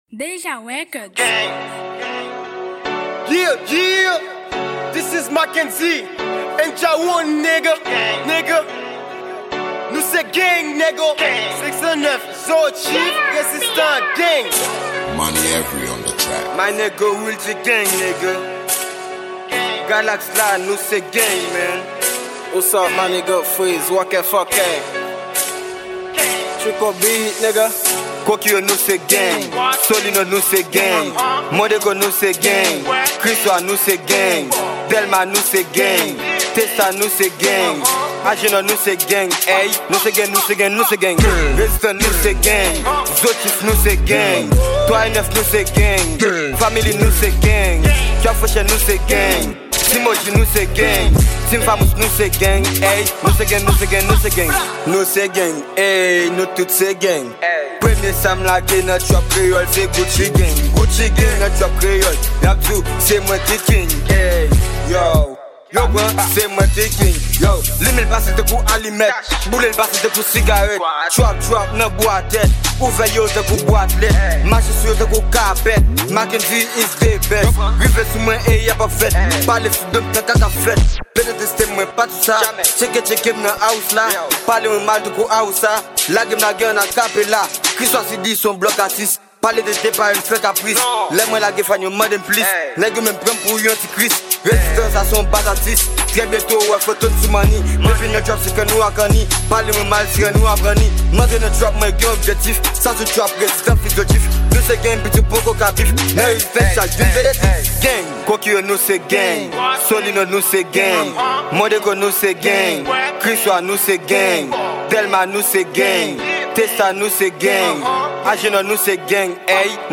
Genre: rrap.